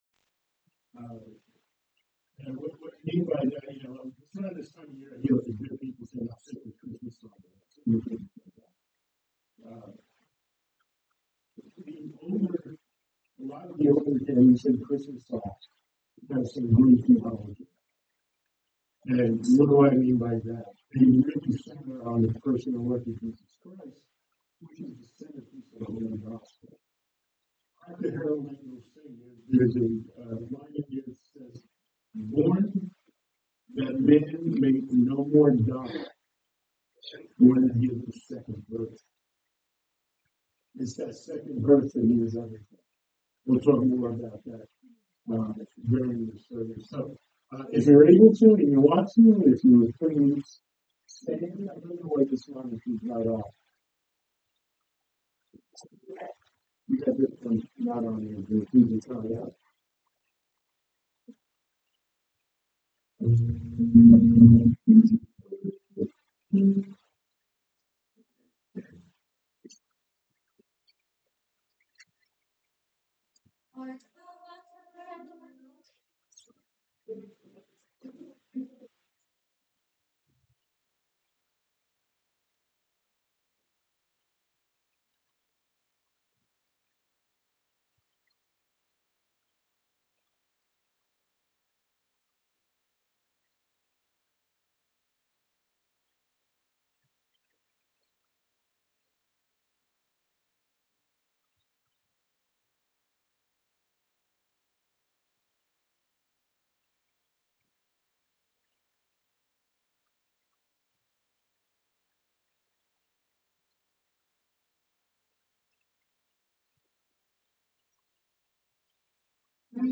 Series: Sunday Morning Worship Service